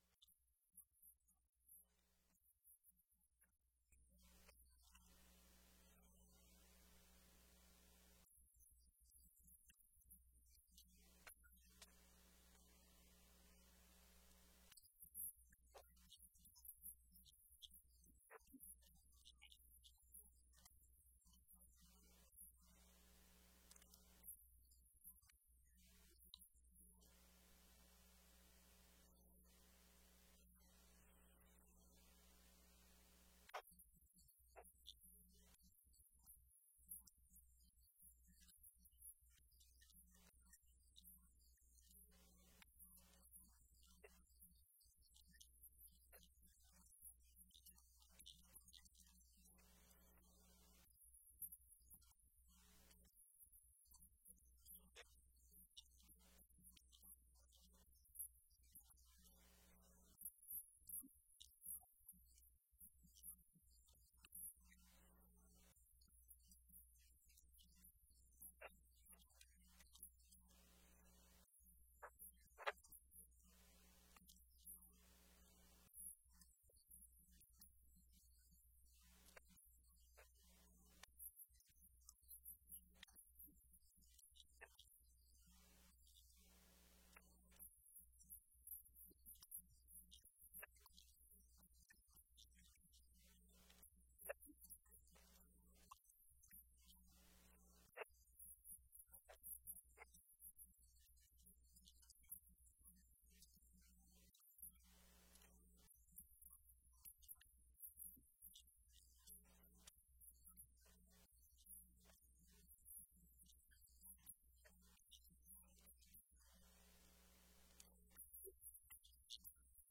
Sermon from Matthew 18:15-20